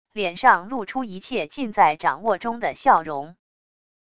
脸上露出一切尽在掌握中的笑容wav音频生成系统WAV Audio Player